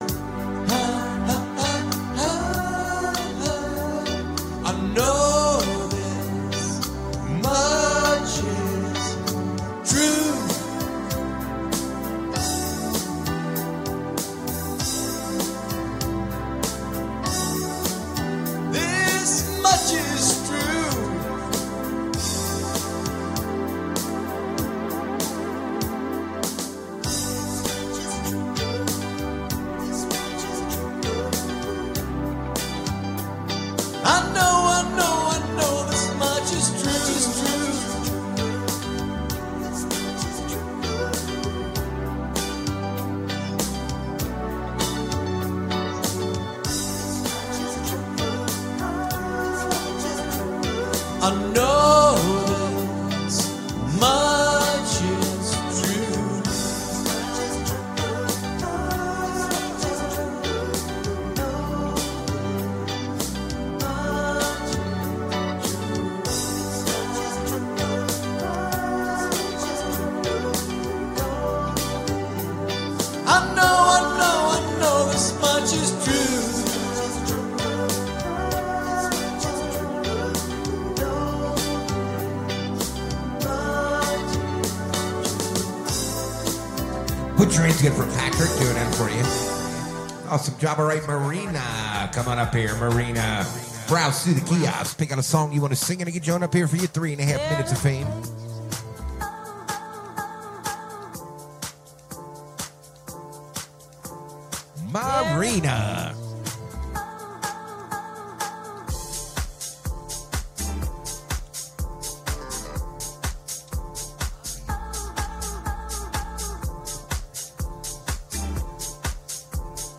We are live 8-12 every Sunday and Wednesday from the Morrison Holiday Bar